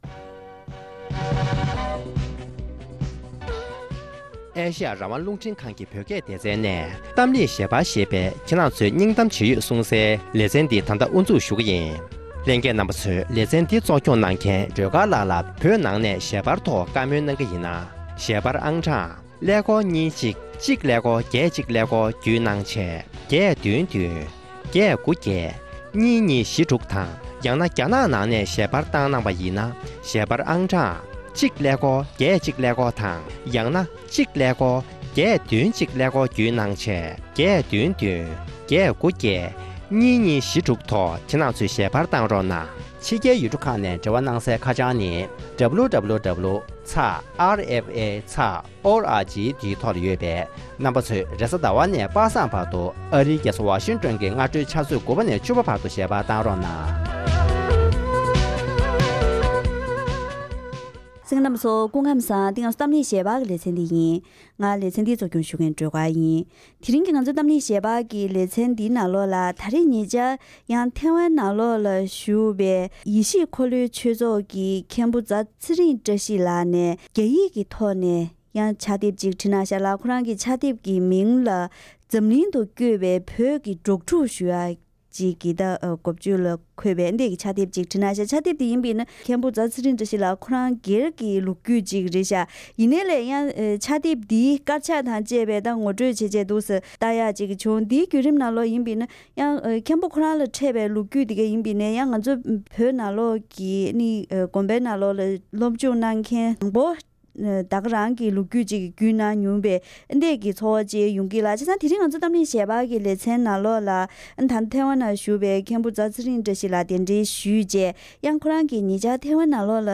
དེ་རིང་གི་གཏམ་གླེང་ཞལ་པར་ལེ་ཚན་ནང་།